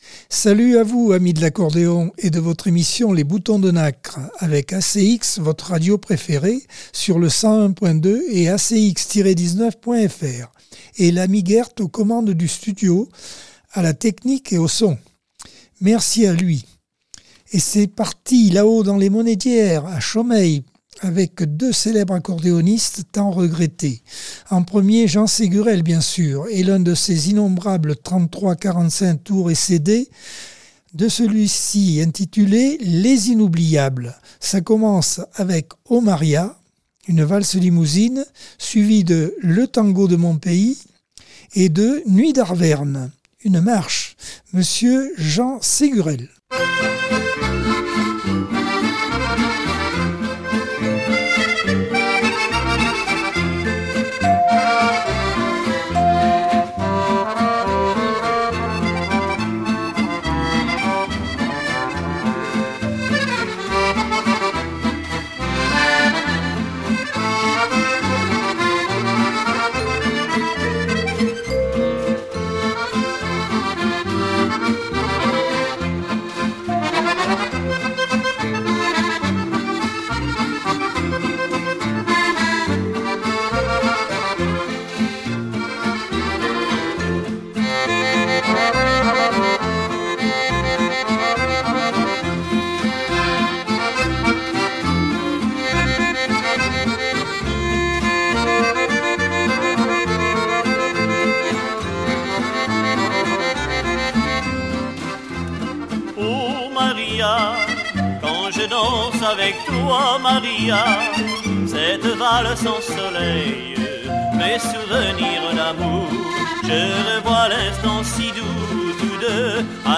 Accordeon 2024 sem 21 bloc 1 - Radio ACX